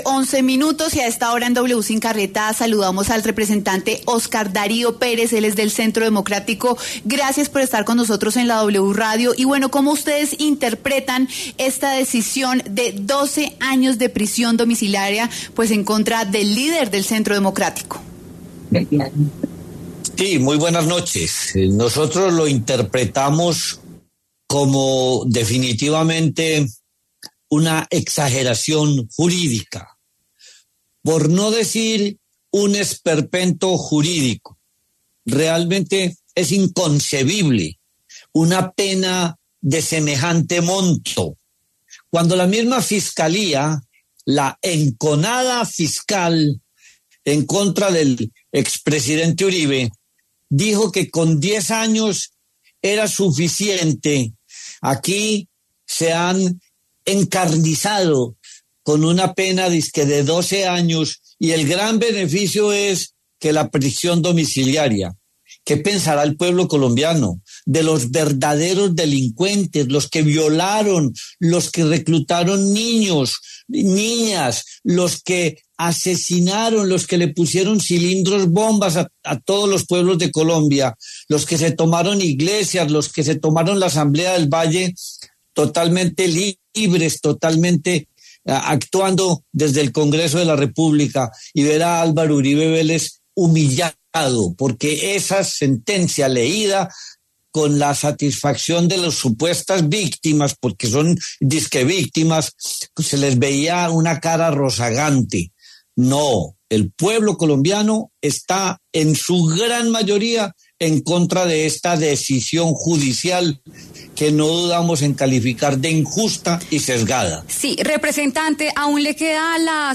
Óscar Darío Pérez, representante por el Centro Democrático, pasó por los micrófonos de W Sin Carreta y habló de la sentencia impuesta por la juez Sandra Heredia en contra del expresidente Álvaro Uribe.